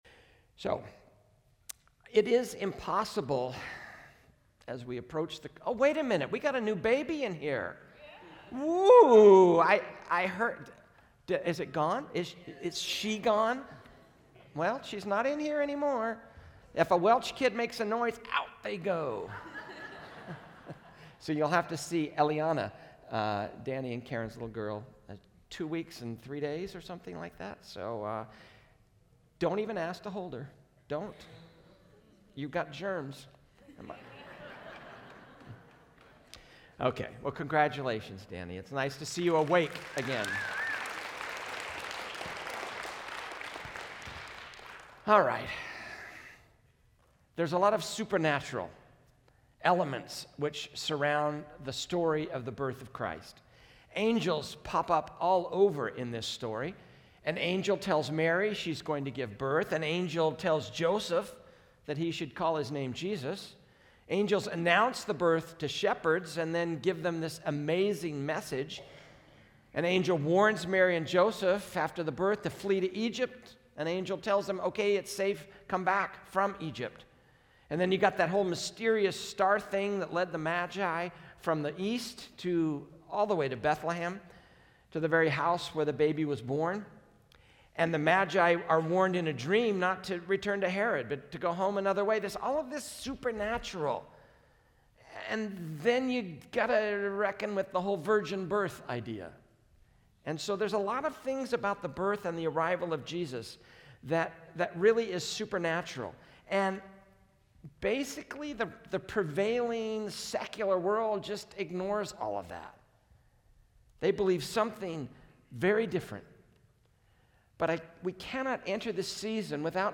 A message from the series "The Unexpected King."